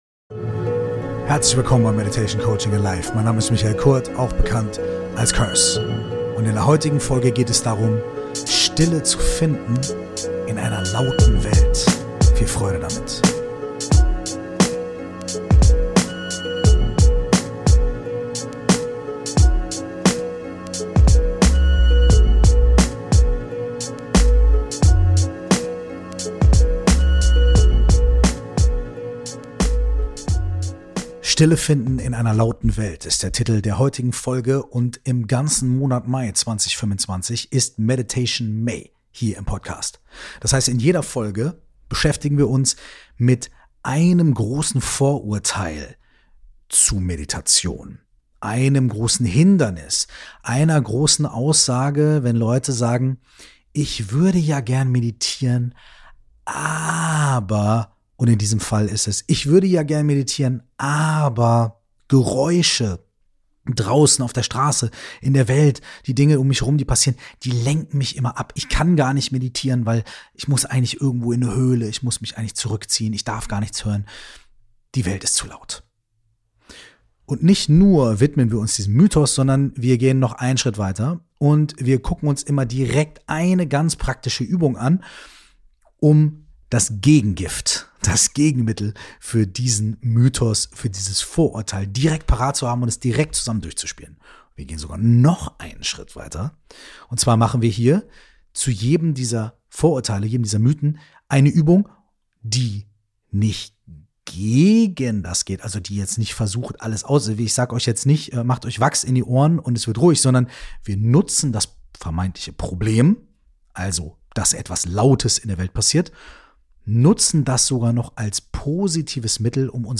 Diesen Monat wirft Curse jede Woche ein Vorurteil über das Meditieren über Bord - und stellt eine geführte Praxis vor, dies das „Problem“ in‘s positive verwandelt.